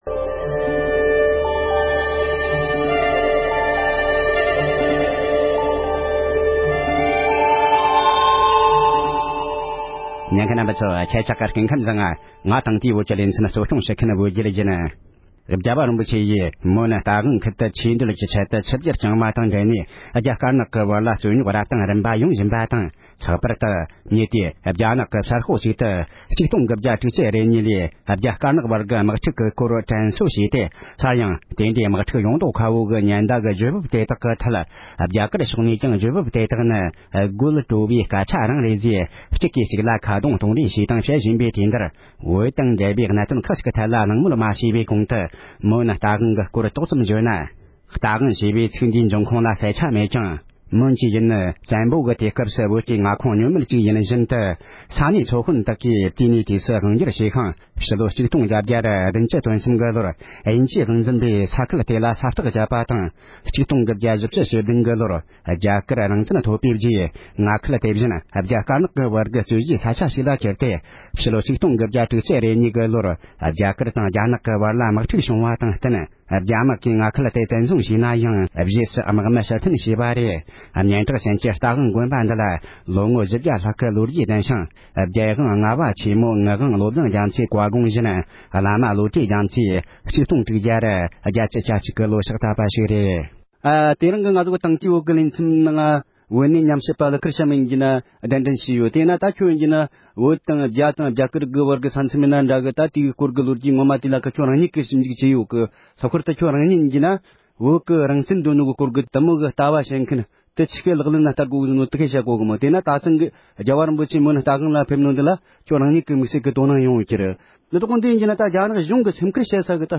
༸གོང་ས་མཆོག་གི་མོན་རྟ་དབང་ཁུལ་དུ་ཆིབས་བསྒྱུར་དང་འབྲེལ་རྒྱ་དཀར་ནག་བར་གྱི་རྩོད་རྙོག་སྐོར་གླེང་མོལ།